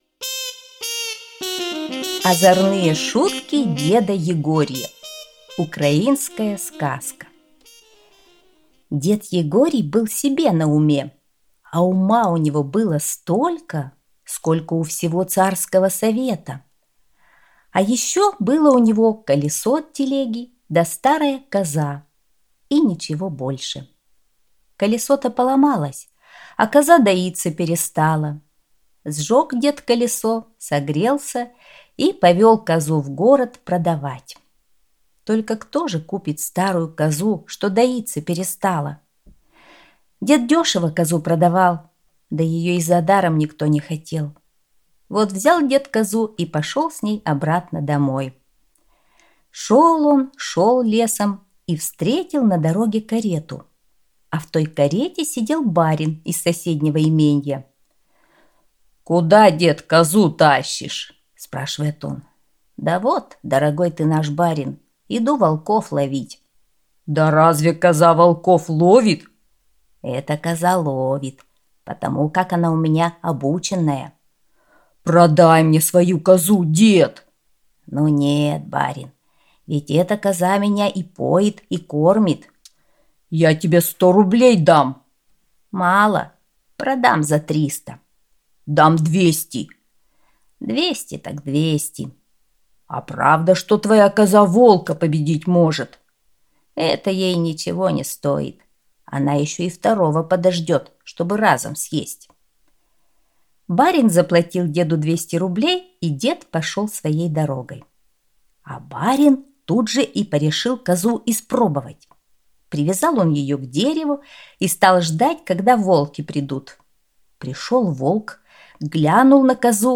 Озорные шутки деда Егория – украинская аудиосказка